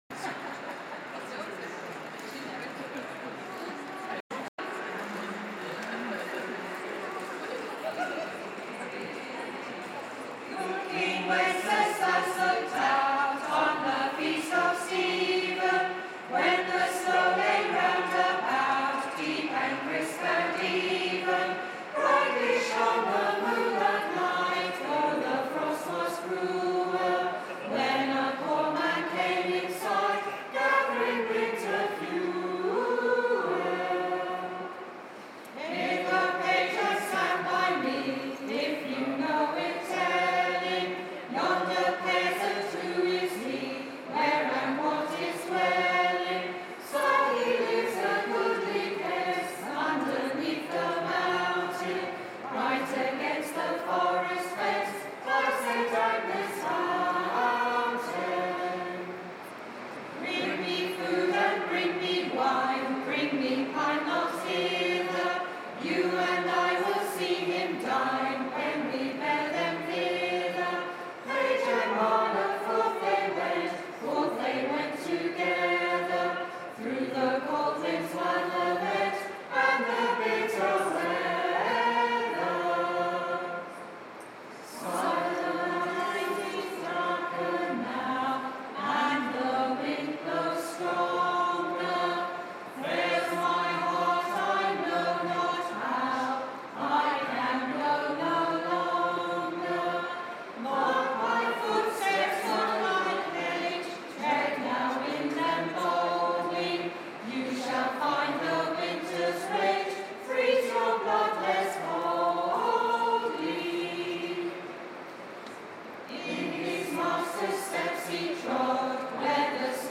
Xmas carols